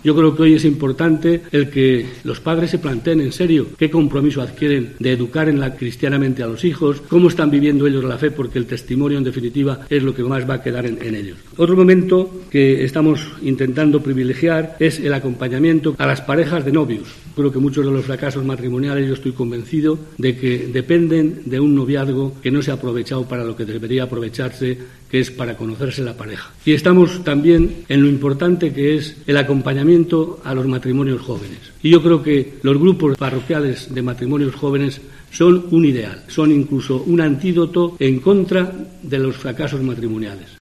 El Obispo de Ciudad Real ha mantenido hoy su tradicional encuentro navideño con los medios de comunicación
Mons. Gerardo Melgar, Obispo de Ciudad Real